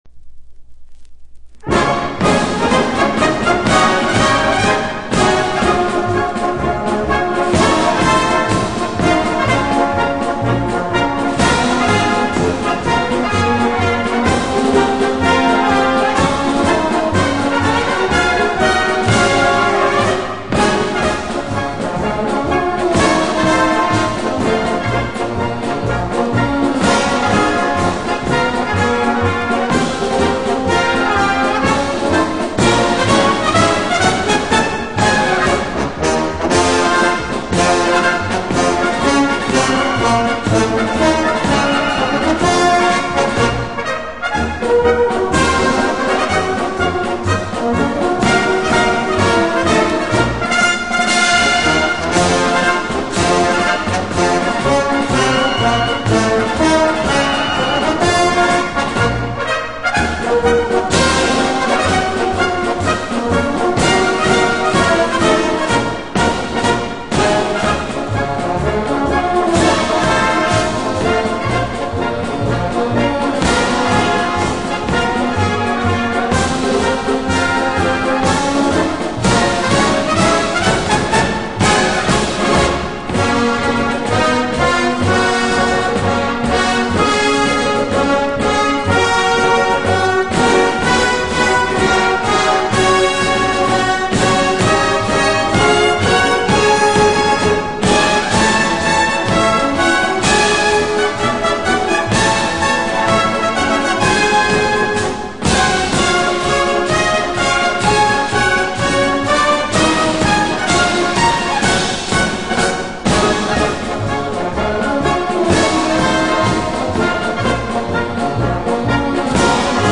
Описание: Знаменитый марш.